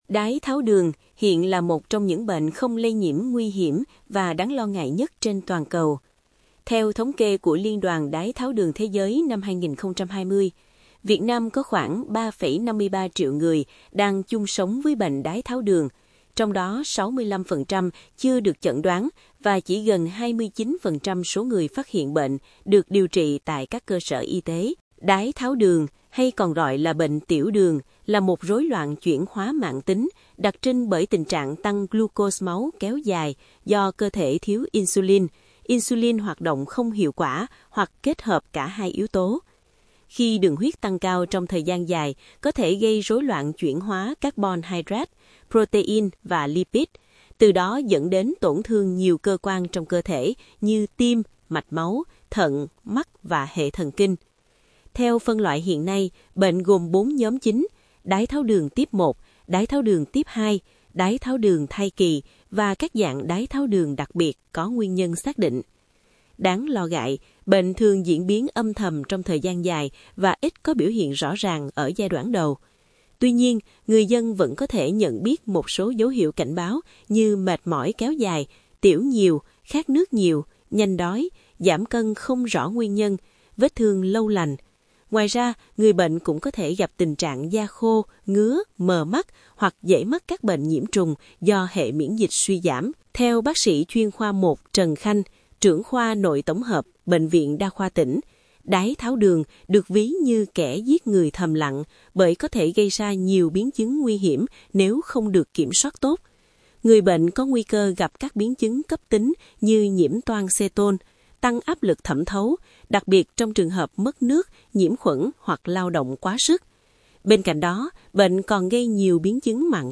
(Phát thanh) Đái tháo đường – “kẻ giết người thầm lặng” với nhiều biến chứng nguy hiểm